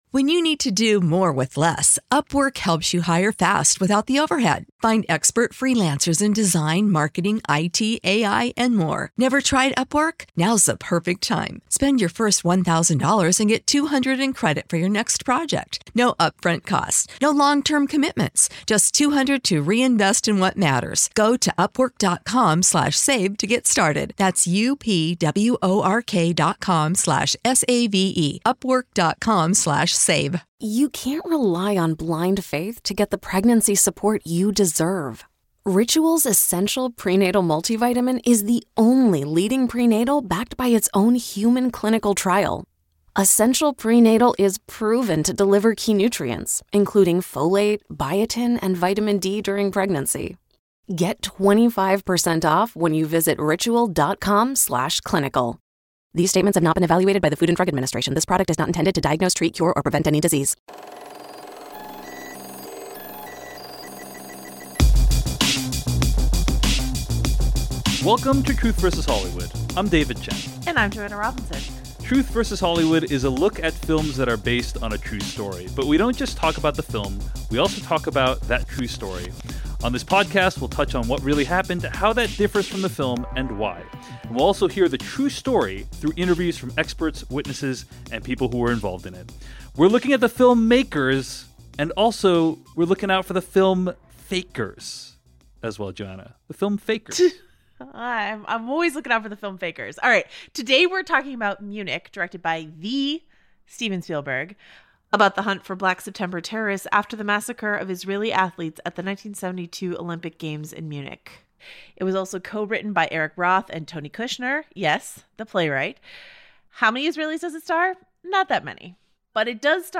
Why are there still no women in this film? Plus we hear from journalists and athletes about what really happened at the Munich Olympic village and why so many things went wrong.